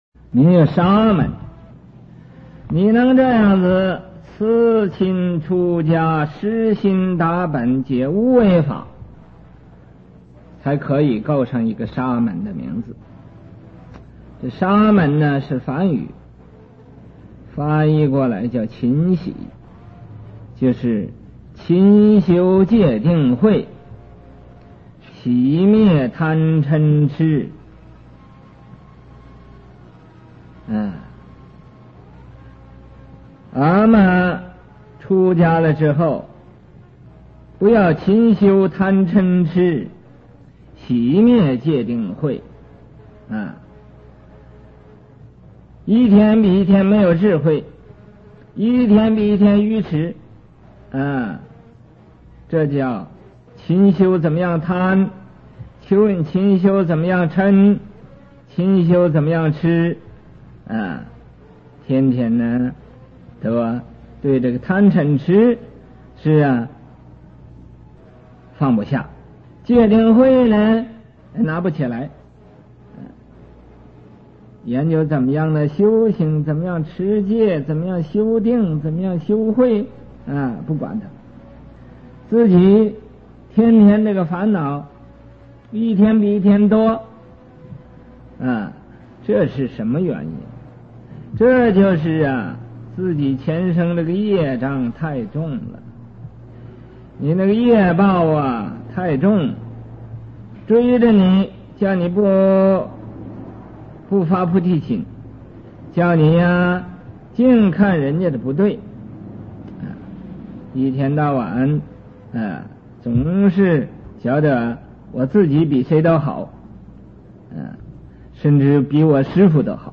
XH_SSRZJ02.mp3 檔案下載 - 佛學多媒體資料庫 佛學多媒體資料庫 > 佛學講座 > 佛學講座-聲音檔 > 宣化上人 > 四十二章經淺釋 > XH_SSRZJ02.mp3 > 檔案下載 Download 下載: XH_SSRZJ02.mp3 ※MD5 檢查碼: CBBBBAD27F305A41E837D70F37635B15 (可用 WinMD5_v2.exe 檢查下載後檔案是否與原檔案相同)